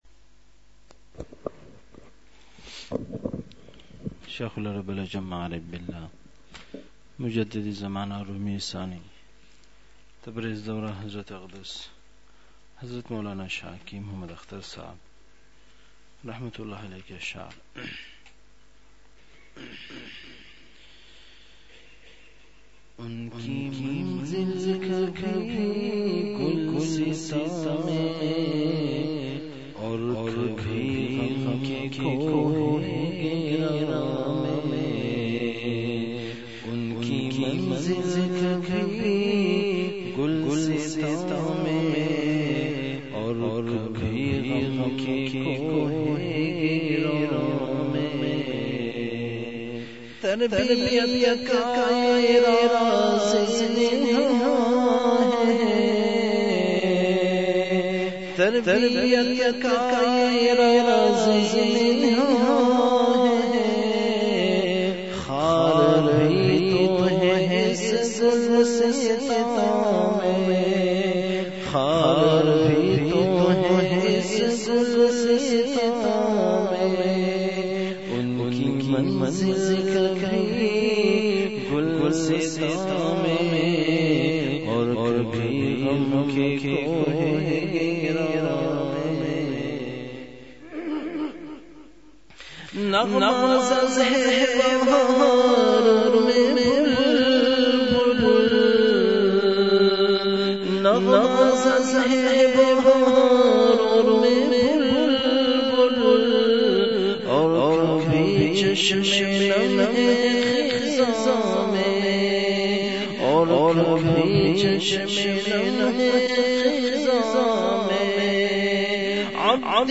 مقام:مسجد اختر نزد سندھ بلوچ سوسائٹی گلستانِ جوہر کراچی